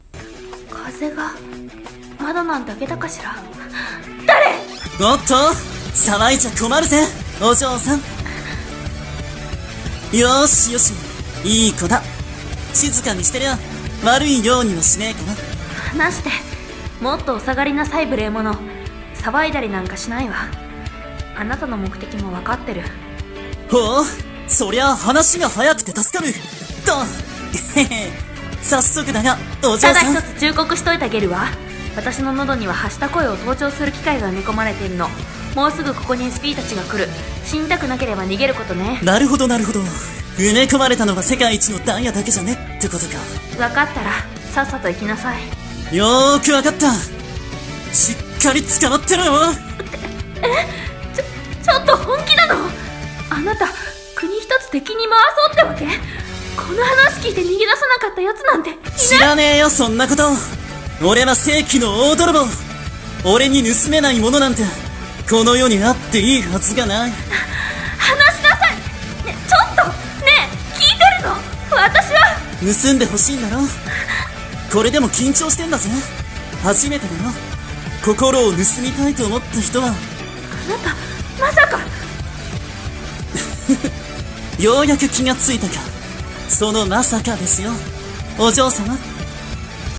コラボ募集【声劇】怪盗とお嬢様